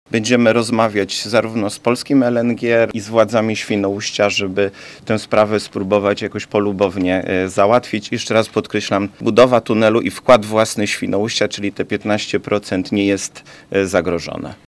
Jak mówi wojewoda zachodniopomorski Krzysztof Kozłowski – inwestycja nie jest zagrożona.